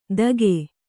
♪ dage